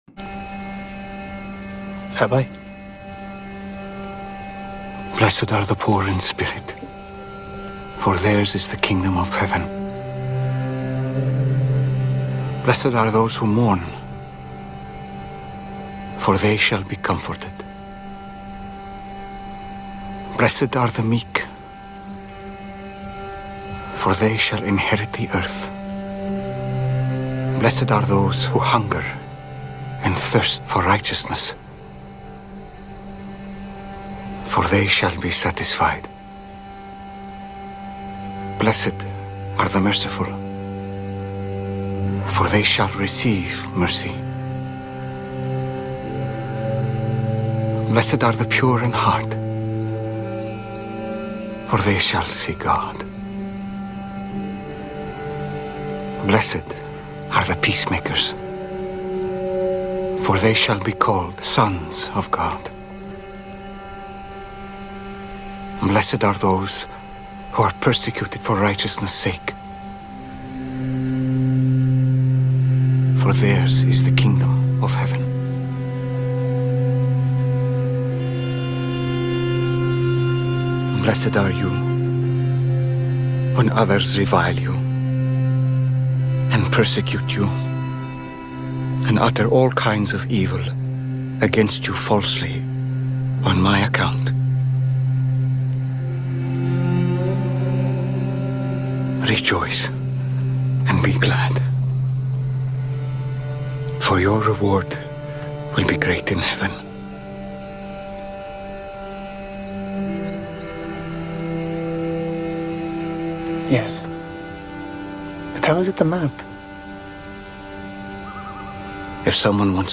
Matthew 5:3-12 Sermon Series: Sermon on the Mount (#2) Speaker